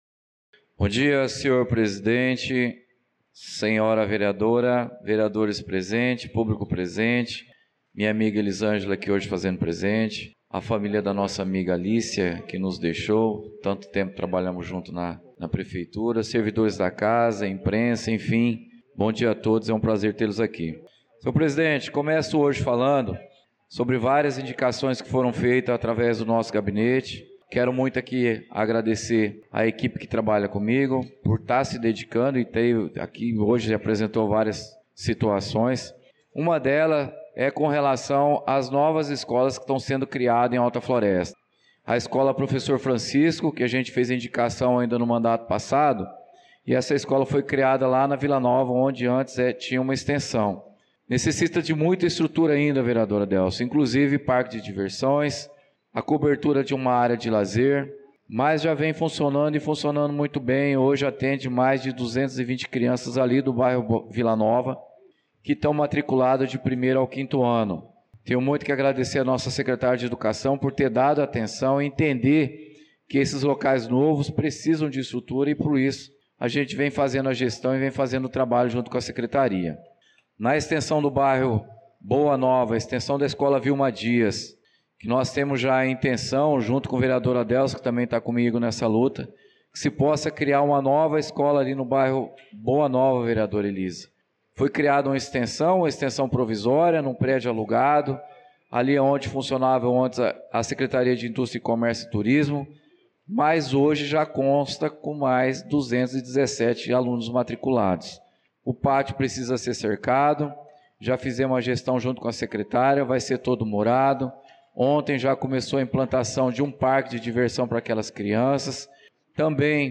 Pronunciamento do vereador Claudinei de Jesus na Sessão Ordinária do dia 25/02/2025